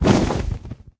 wings6.ogg